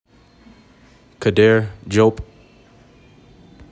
Pronunciation:  kuh DEER JOPE